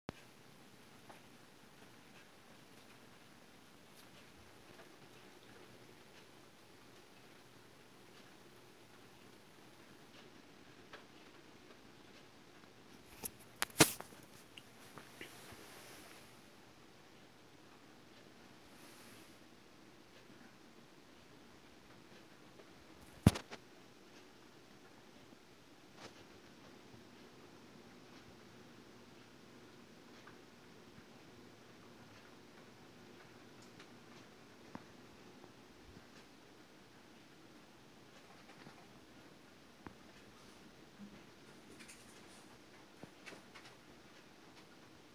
Field Recording #1
Sounds heard: Faint rain bouncing off my window, Clock ticking, Dog running at the end.